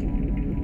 whirring.wav